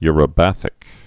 (yrə-băthĭk)